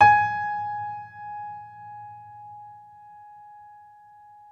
Vintage_Upright
gs4.mp3